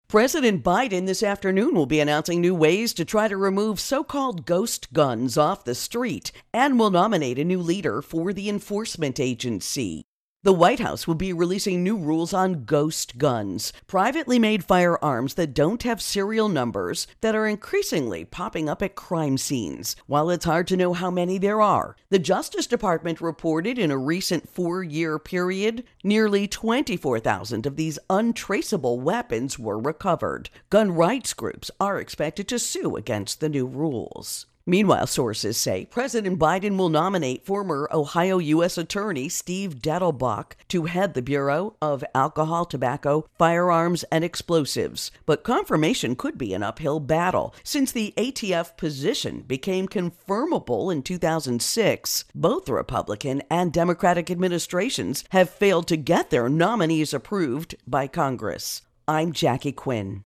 Biden Guns Intro and Voicer